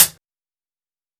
TC3Hat5.wav